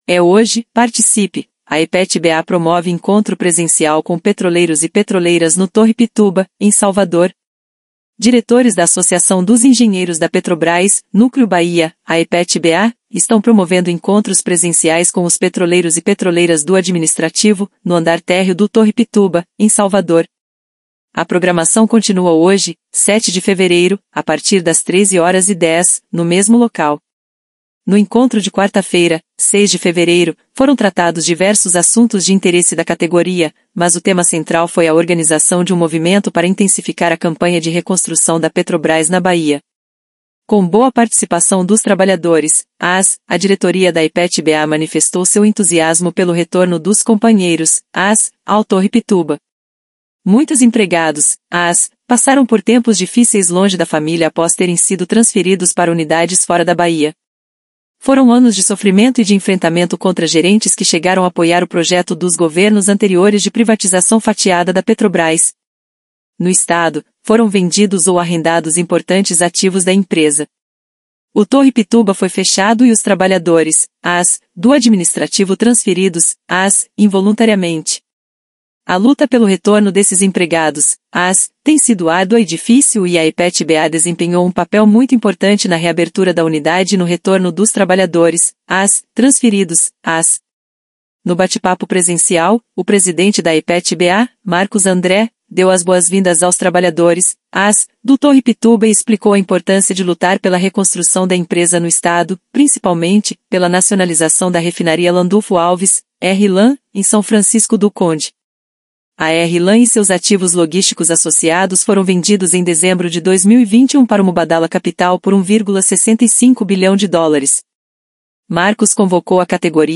Diretores da Associação dos Engenheiros da Petrobrás, Núcleo Bahia (AEPET-BA), estão promovendo encontros presenciais com os petroleiros e petroleiras do administrativo, no andar térreo do Torre Pituba, em Salvador.
1-bate-papo-com-petroleiros-torre-pituba.mp3